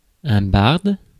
Ääntäminen
IPA: /baʁd/